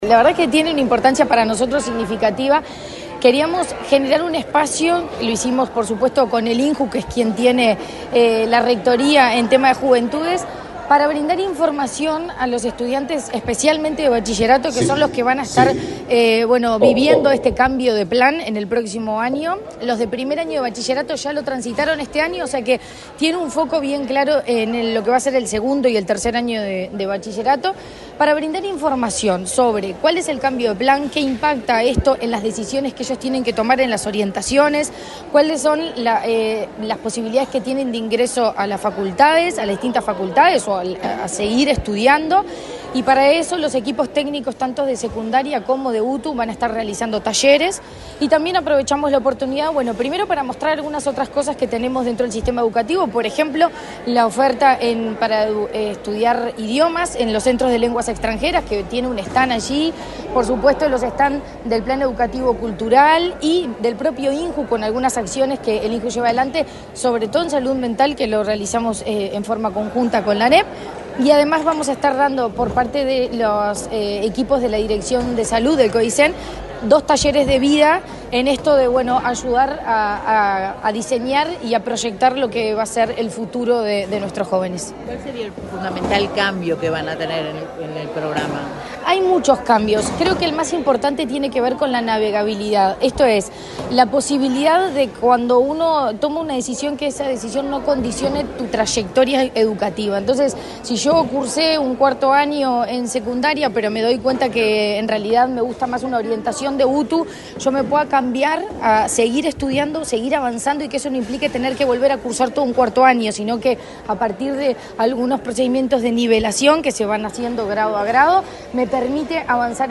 Declaraciones de la presidenta de ANEP, Virginia Cáceres
La presidenta de la NEP, Virginia Cáceres, dialogó con la prensa antes de la apertura del evento.